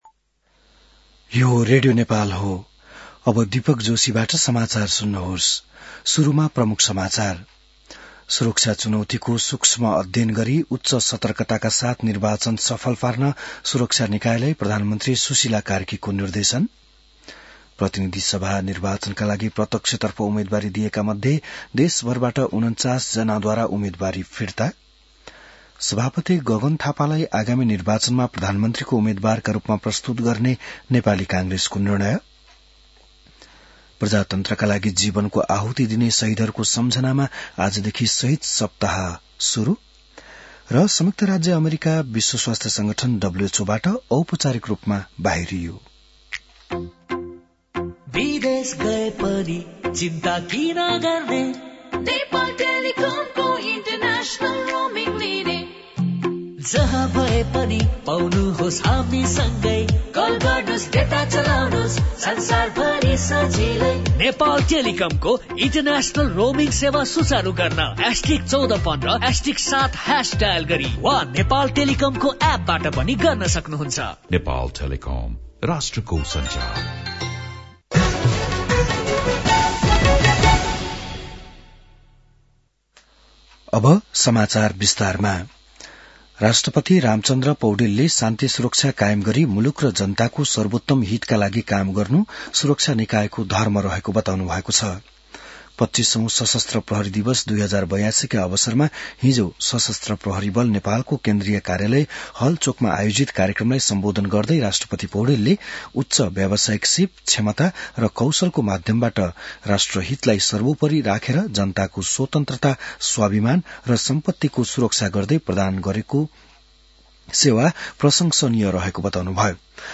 बिहान ७ बजेको नेपाली समाचार : १० माघ , २०८२